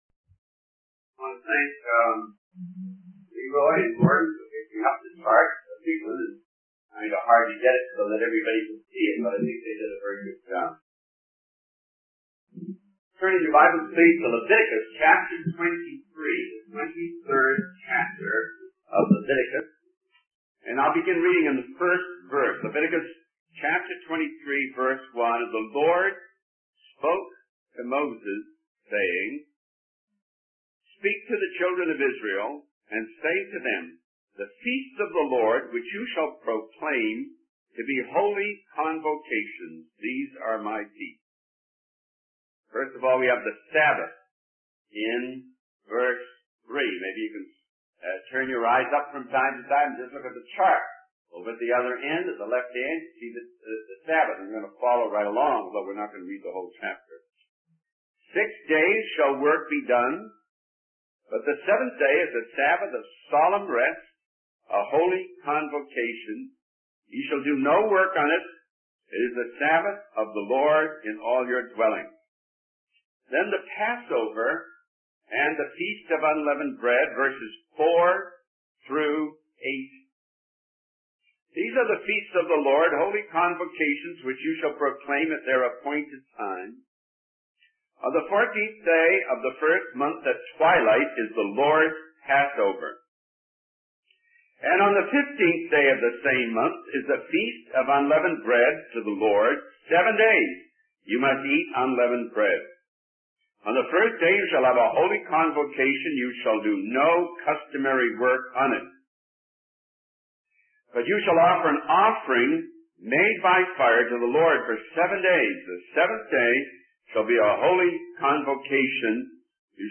In this sermon, the speaker discusses the concept of three days and three nights in relation to Jesus' resurrection. He explains that according to Jewish reckoning, a part of a day counts as a whole day.